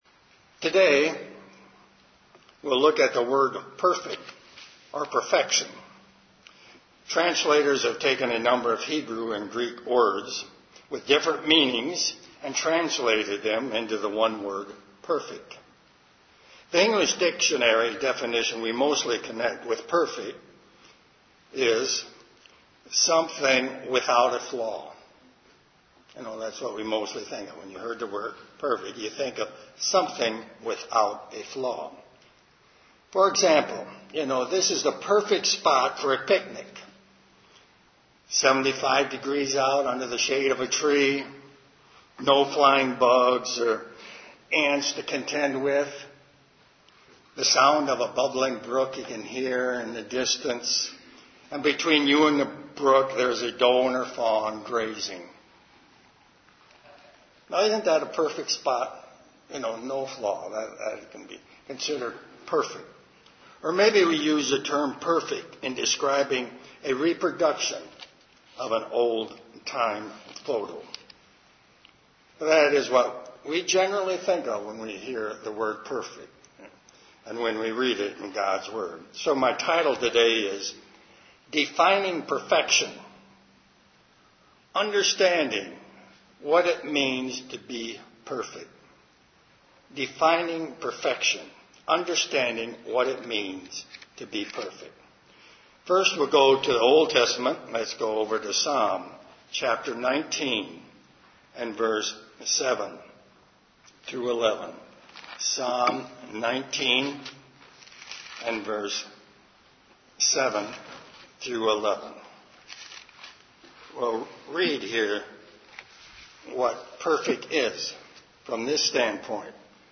Sermons
Given in Detroit, MI